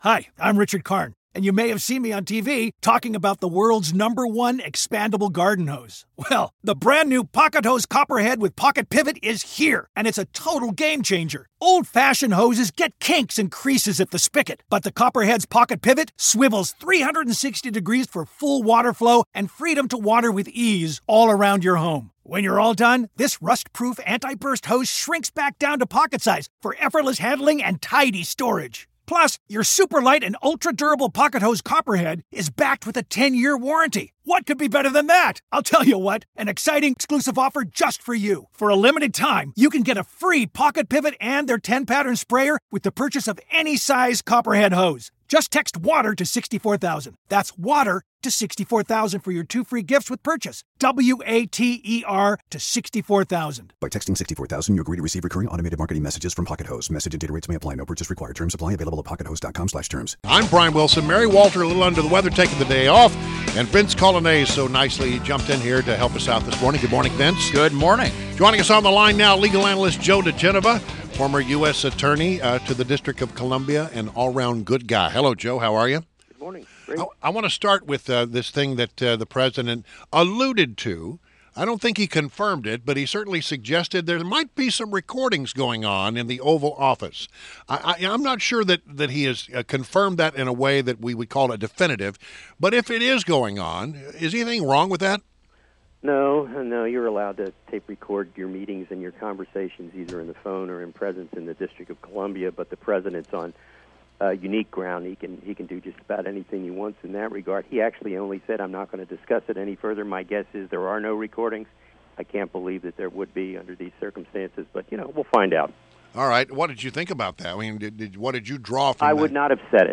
INTERVIEW — JOE DIGENOVA – legal analyst and former U,.S. Attorney to the District of Columbia